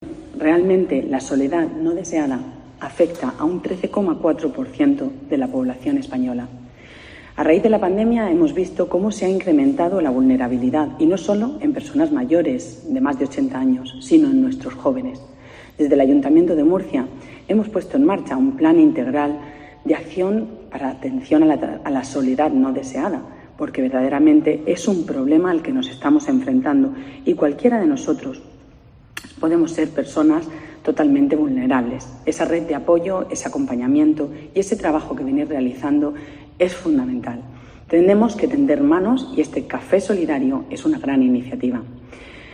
PIlar Torres, concejala de Bienestar Social, Familia y Salud, Pilar Torres